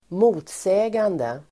motsägande adjektiv, conflicting , contradictory Uttal: [²m'o:tsä:gande (el. -sej:-)]